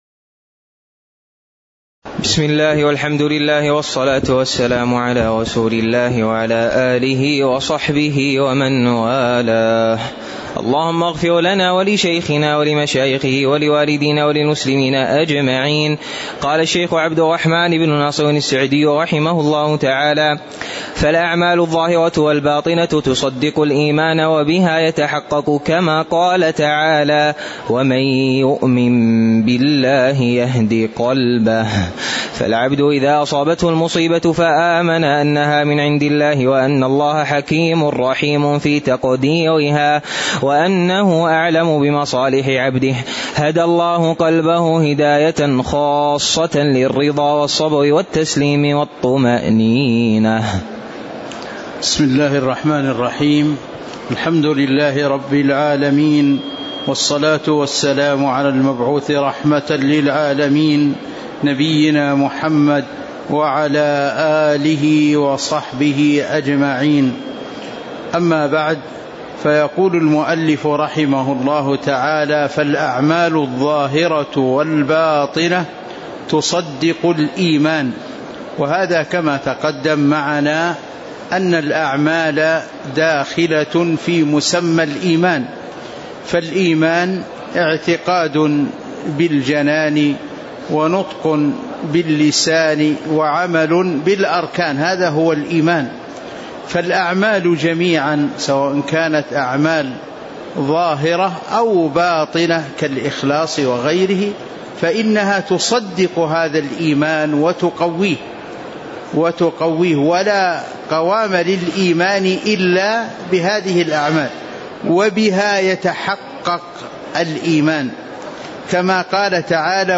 تاريخ النشر ٢١ ربيع الثاني ١٤٤٥ هـ المكان: المسجد النبوي الشيخ